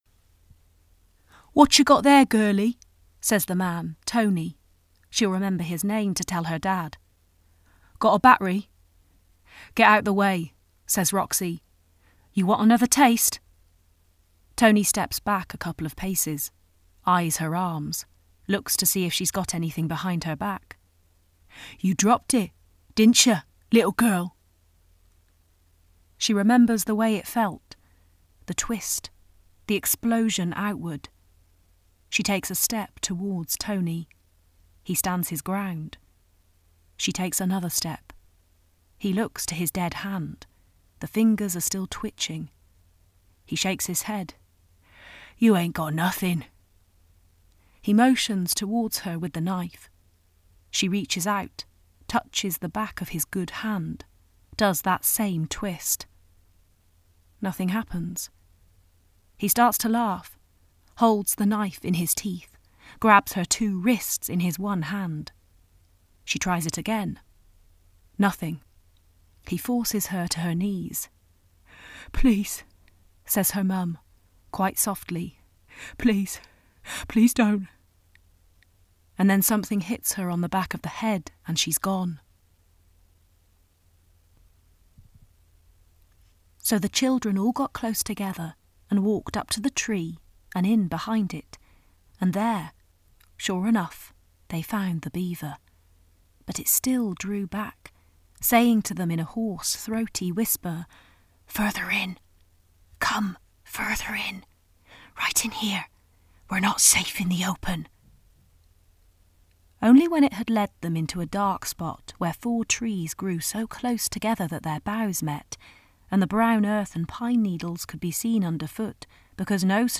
• Native Accent: Hereford, West Country
• Home Studio